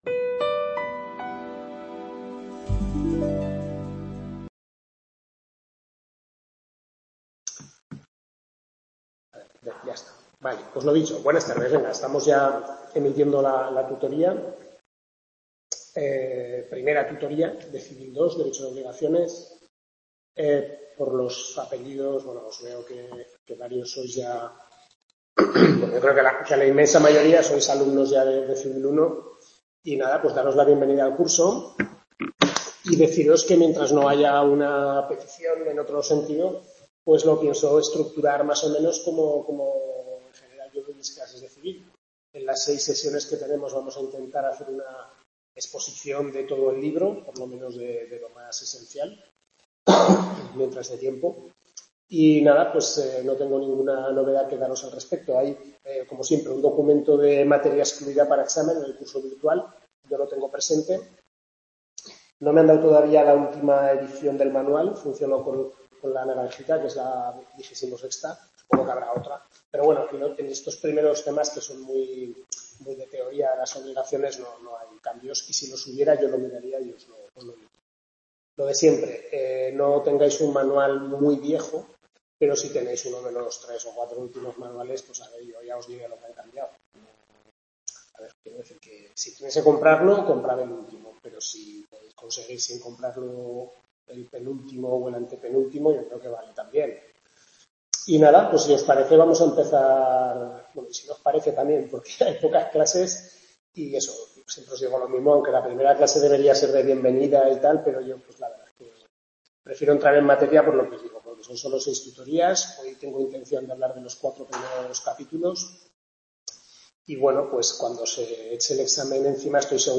Tutoría 1/6 Obligaciones